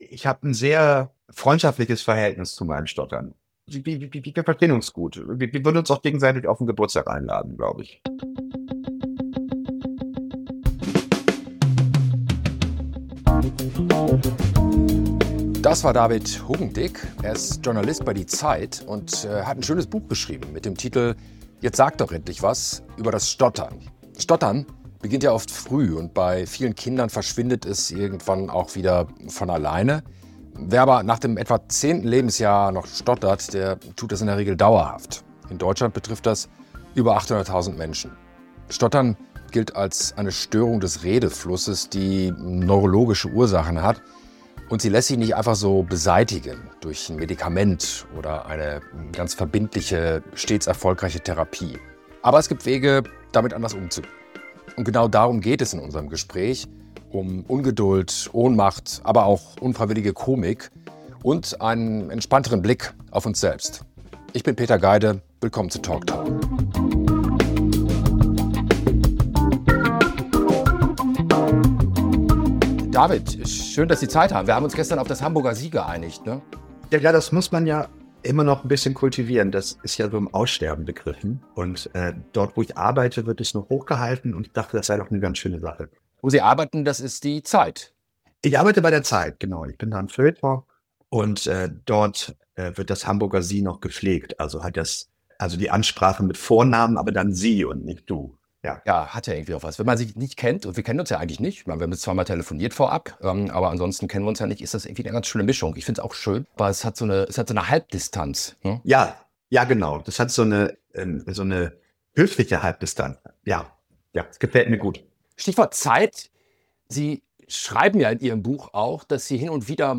Ein Gespräch über Worte, Warten, unfreiwillige Komik und die Ungeduld einer Gesellschaft, die das Wie der Kommunikation oft höher bewertet als das Was.